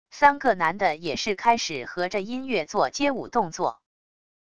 三个男的也是开始和着音乐做街舞动作wav音频